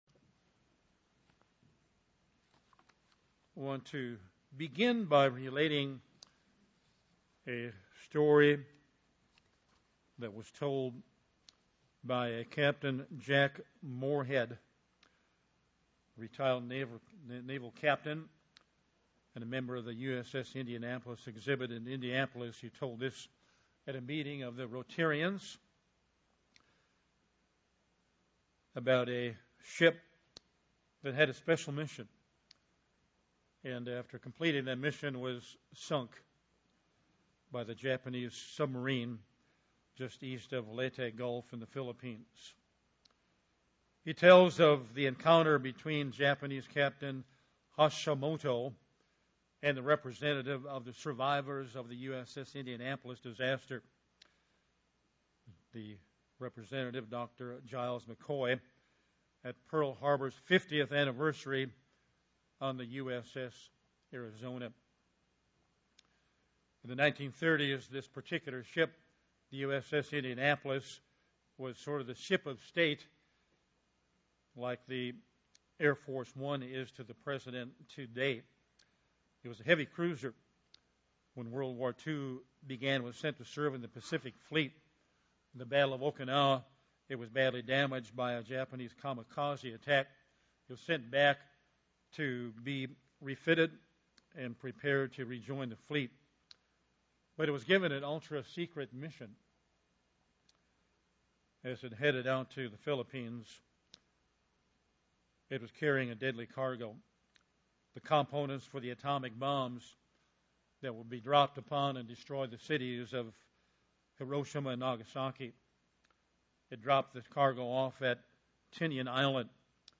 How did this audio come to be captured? Given in Bismarck, ND